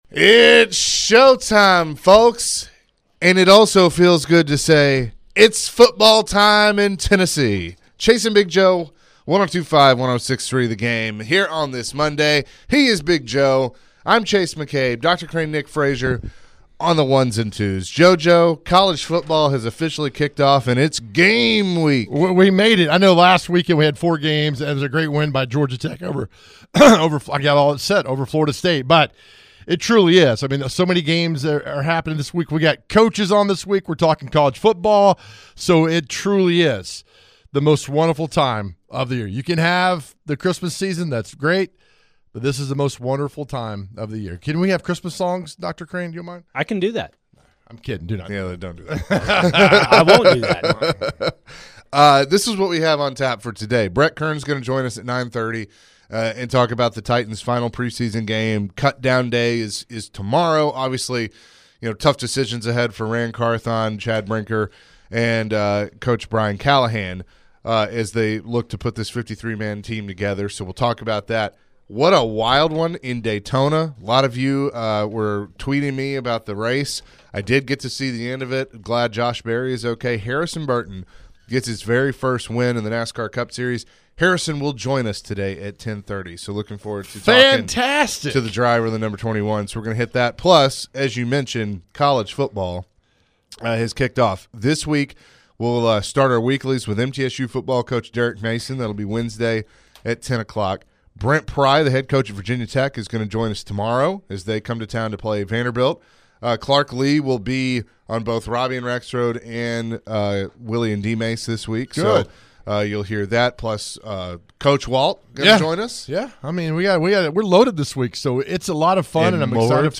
Later in the hour NASCAR driver Harrison Burton joined the show. Harrison was asked about the crazy finish to the race yesterday.